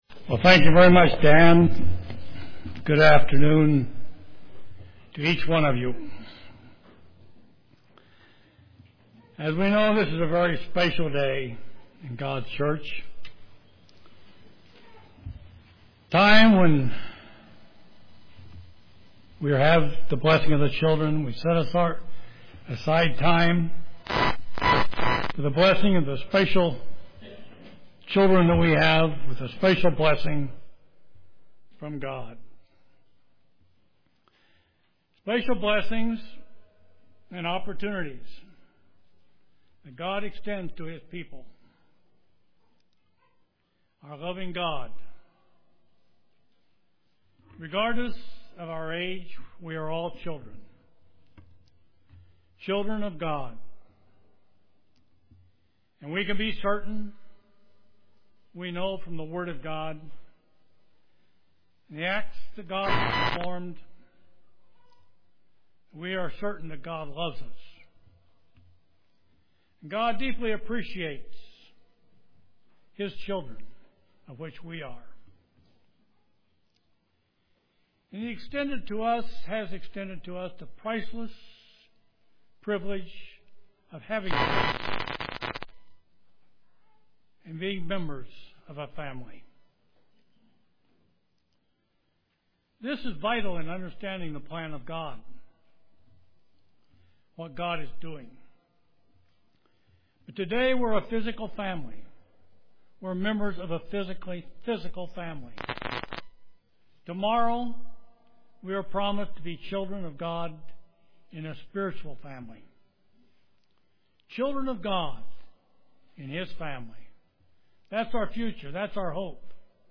Given in Kansas City, KS
UCG Sermon Studying the bible?